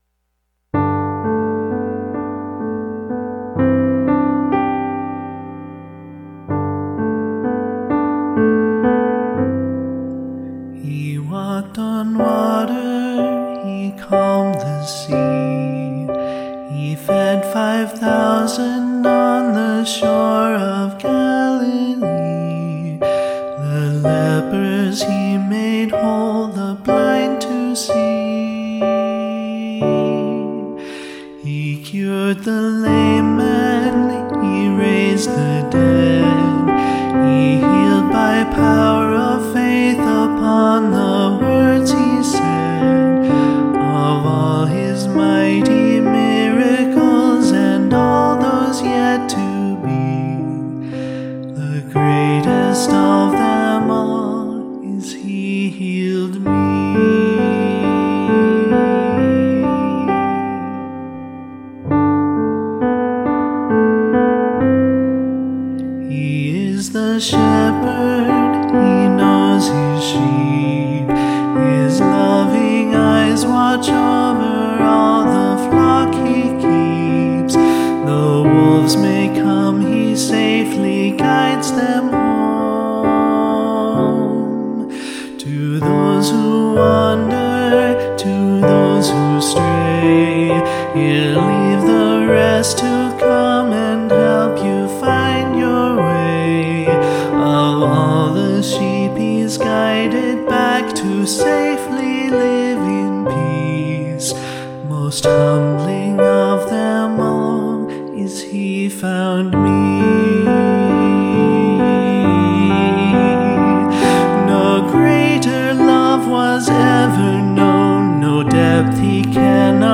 Men's solo with piano accompaniment.
Vocal Solo Medium Voice/Low Voice